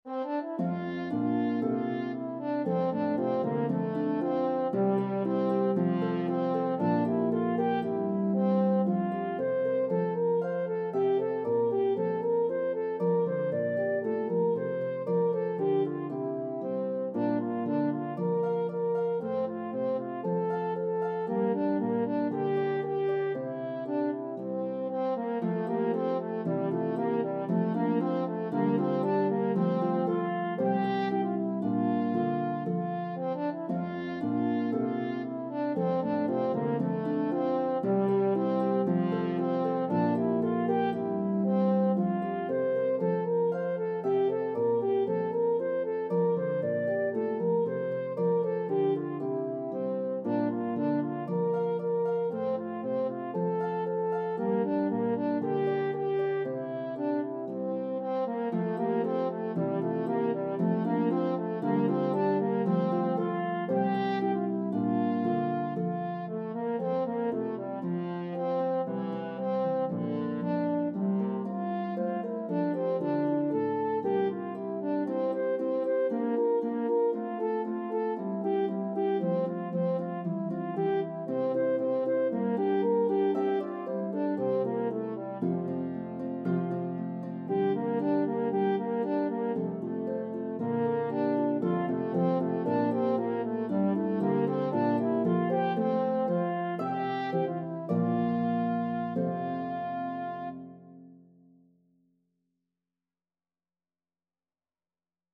Harp and French Horn version